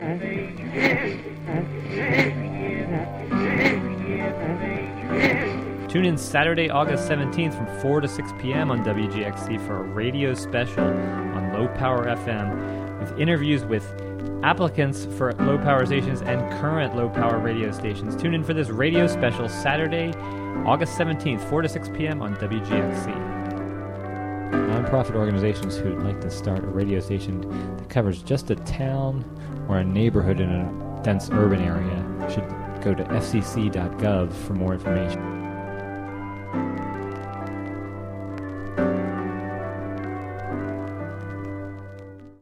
An Official Promo for "Saturday Afternoon Show" Low-Power FM Radio Special Sat., Aug. 17, 4-6 p.m. (Audio)